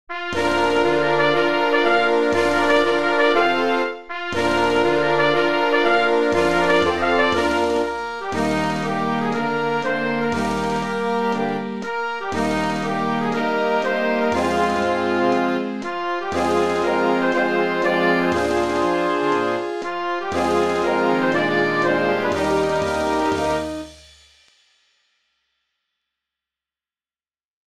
Niveau de difficulté : Facile
Collection : Harmonie (Marches)
Marche-parade pour harmonie-fanfare,
avec tambours et clairons ad lib.